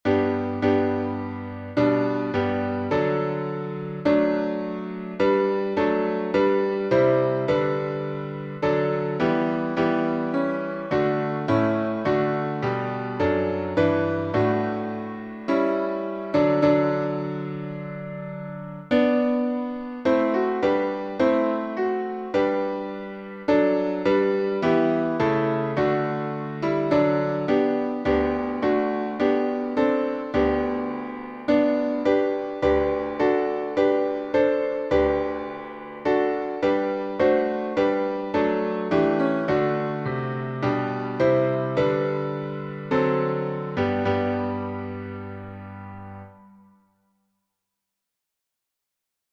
#1046: O Come, All Ye Faithful — Four stanzas in G | Mobile Hymns